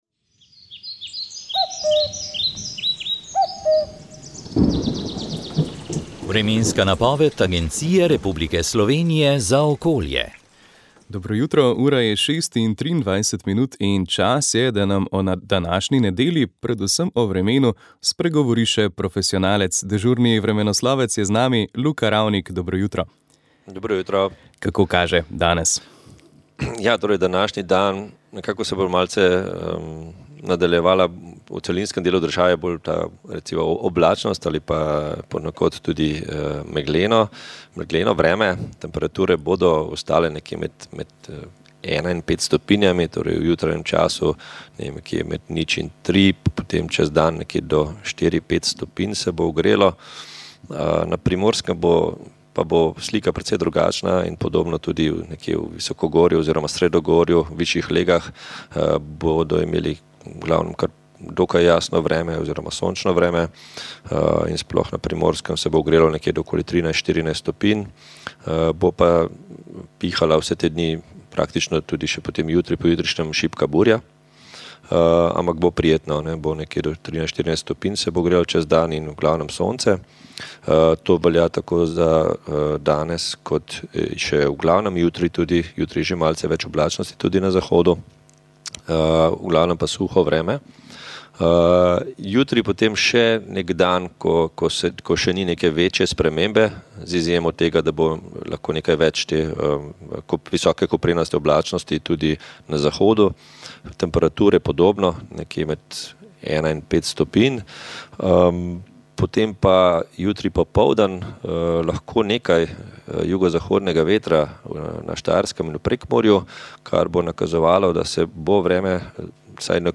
Vremenska napoved